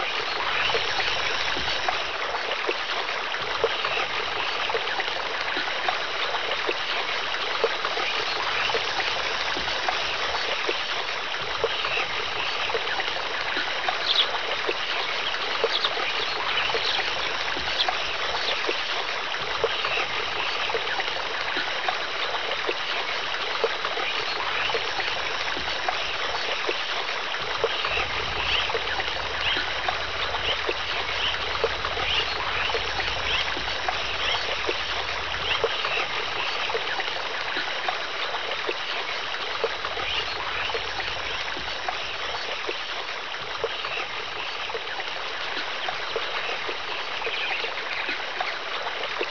swamped_loop.wav